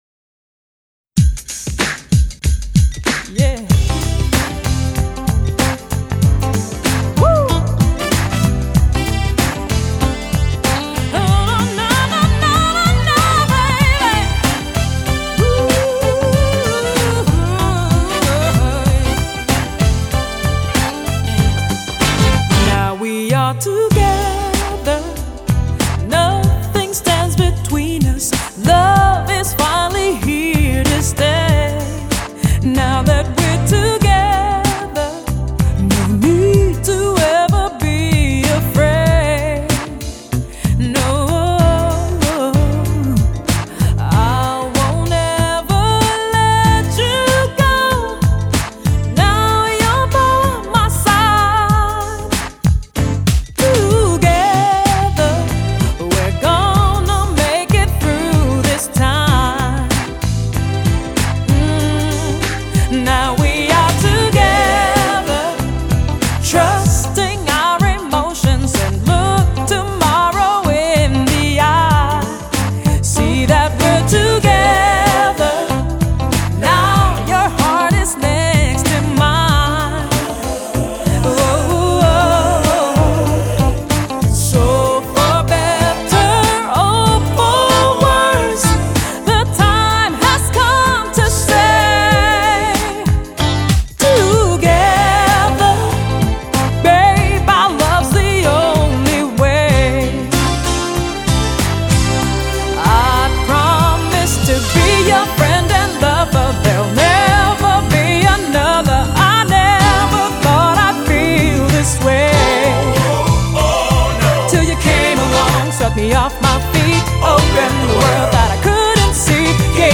Performed: All instruments except Sax and Keyboards